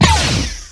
blaster_fire.WAV